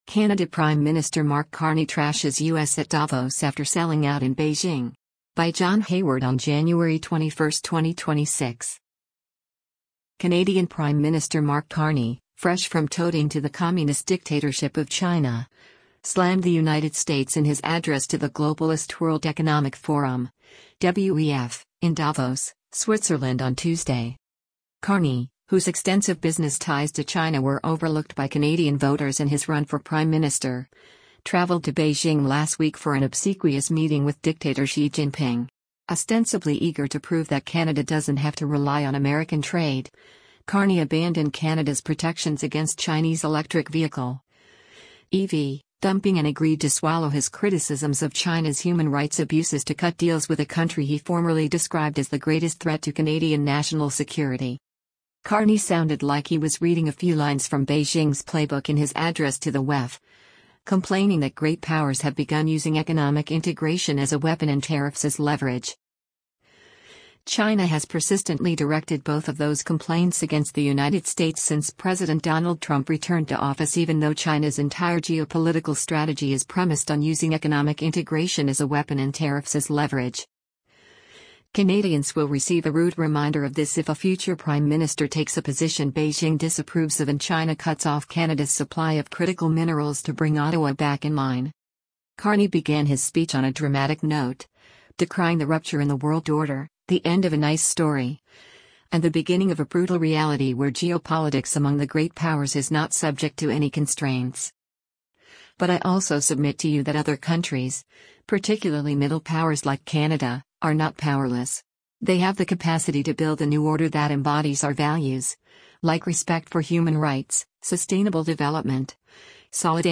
DAVOS, SWITZERLAND - JANUARY 20: Canadian Prime Minister Mark Carney delivers a speech at